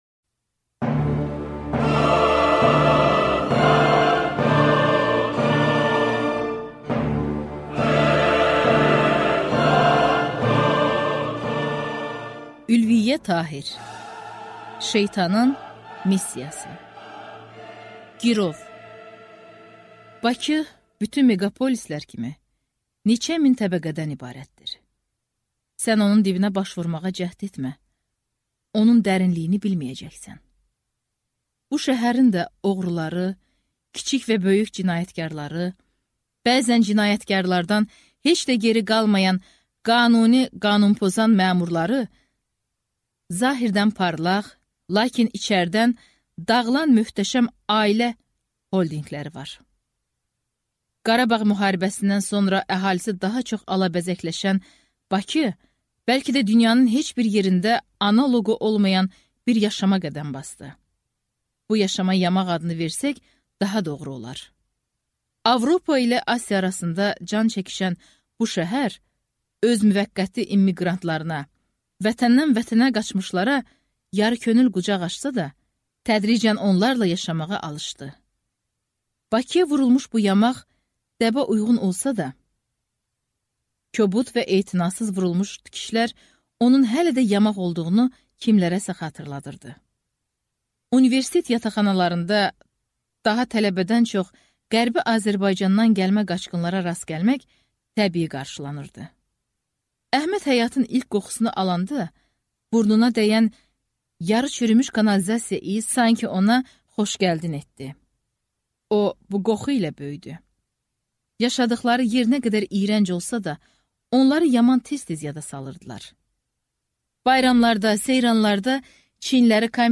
Аудиокнига Şeytanın missiyası | Библиотека аудиокниг
Прослушать и бесплатно скачать фрагмент аудиокниги